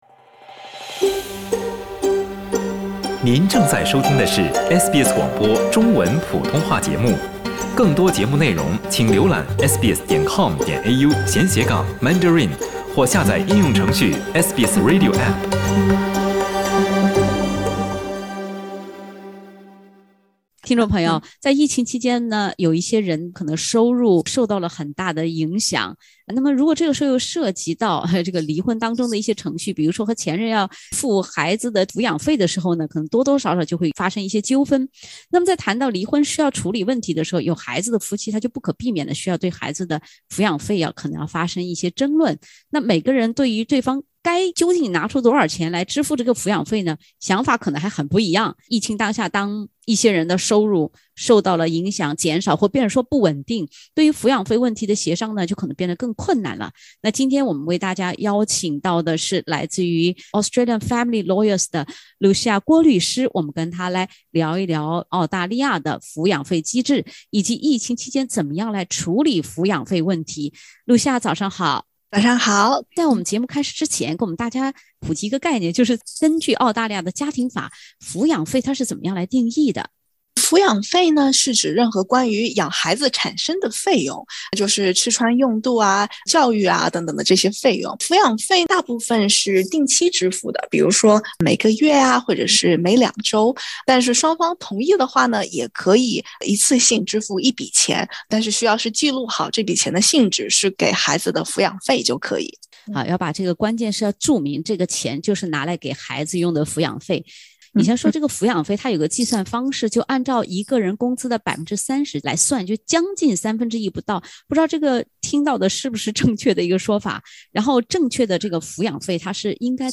律師分享疫情下噹收入減少或變得不穩定，應如何與前任妥善協商處理撫養費。（點擊封面圖片，收聽完整對話）